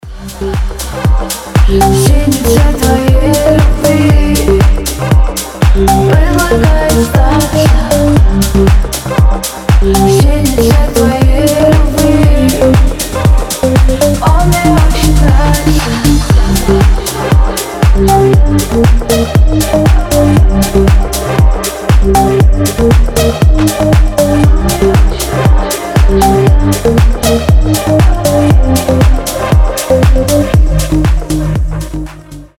• Качество: 320, Stereo
deep house
чувственные